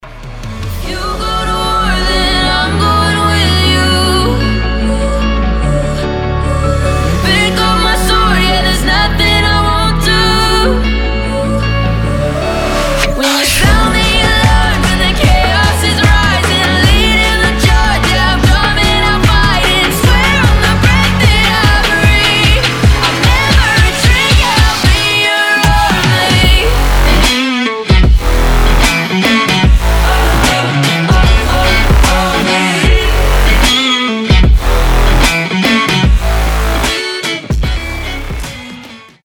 • Качество: 320, Stereo
гитара
громкие
Electronic
мощные басы
нарастающие
взрывные
красивый женский голос